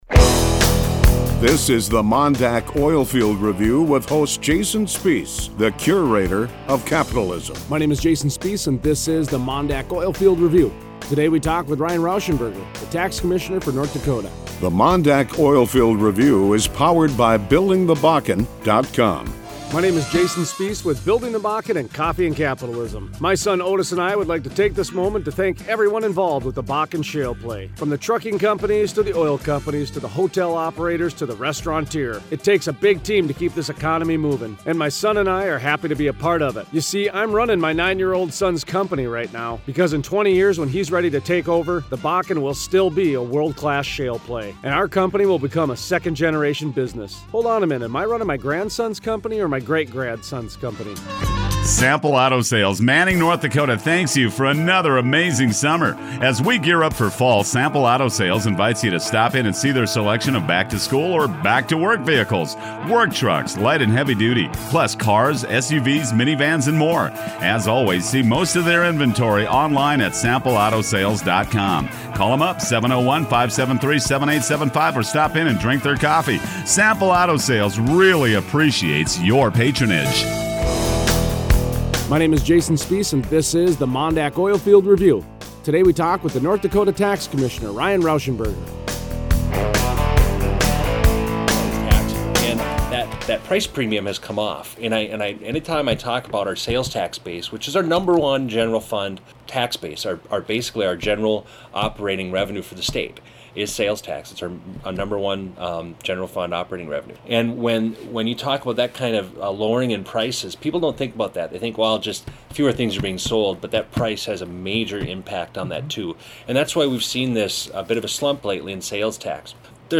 Wednesday 10/28 Interview: Ryan Rauschenberger, ND Tax Commissioner Explains how the eastern taxable sales are up balancing out the missing tax revenue in western ND.